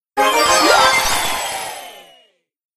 brawl_teammode_rank_up_01.ogg